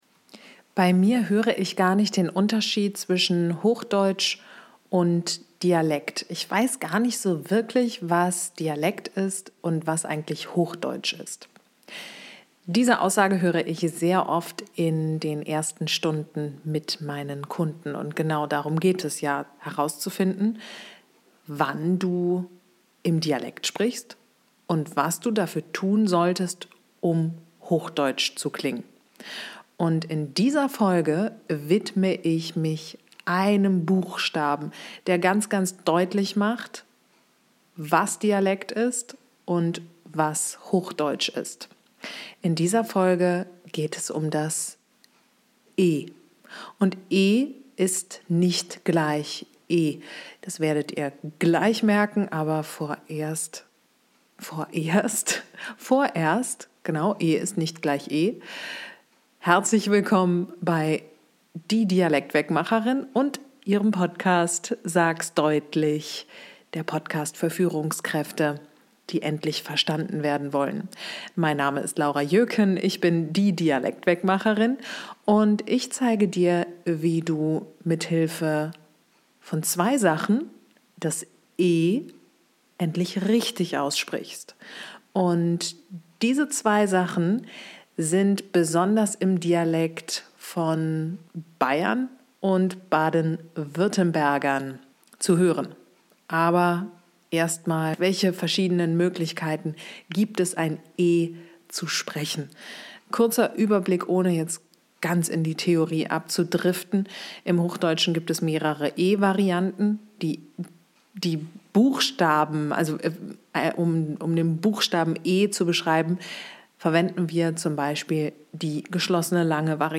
Im Dialekt wird dieses E oft betont, lang gezogen und geschlossen
Im Hochdeutschen ist es unbetont, kurz und offen.